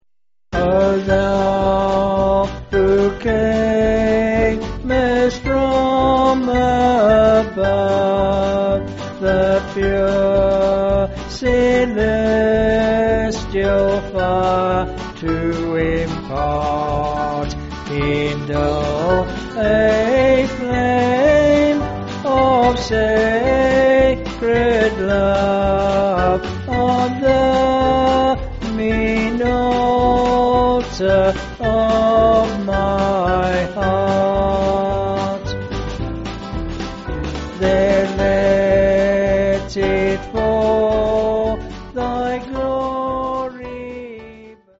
4/G-Ab
Vocals and Band